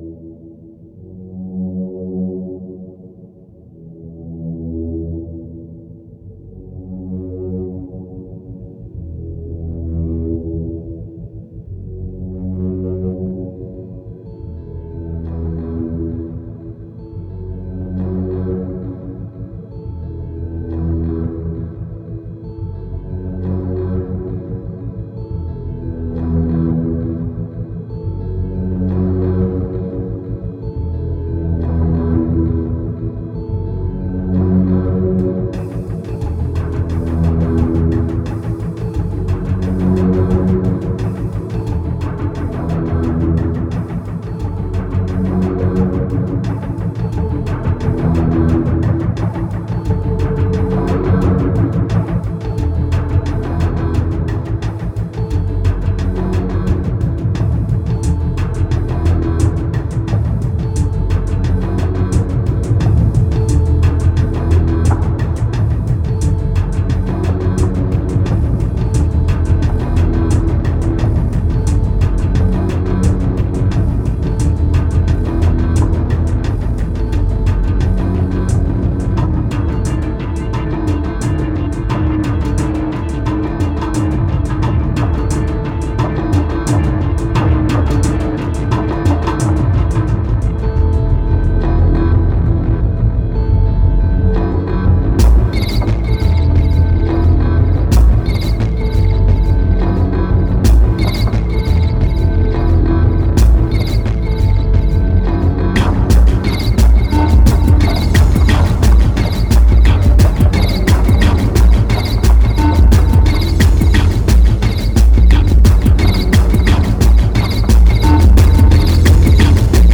2075📈 - 5%🤔 - 88BPM🔊 - 2011-04-09📅 - -146🌟